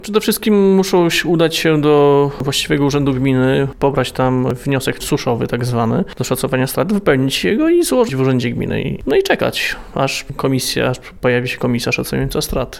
Deszcz już nie uratuje plonów – mówili dziś na antenie Radia 5